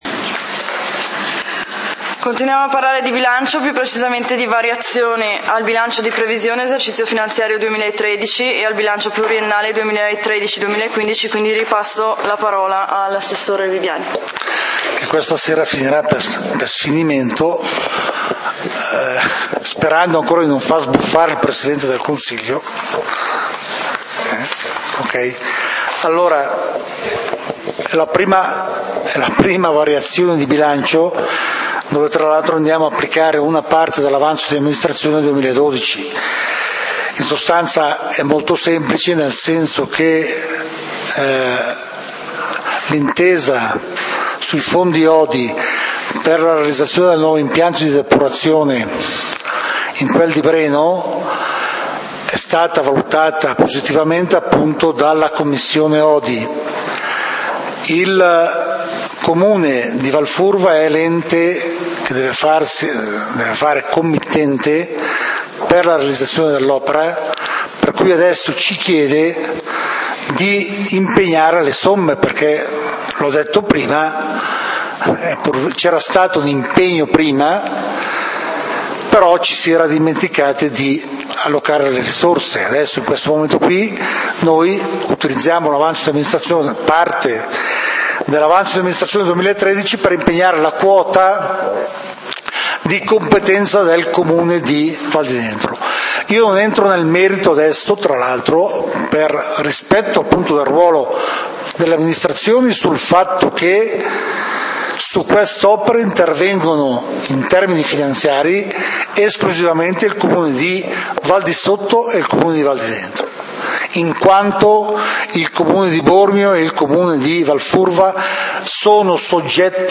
Punti del consiglio comunale di Valdidentro del 01 Agosto 2013